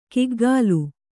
♪ kiggālu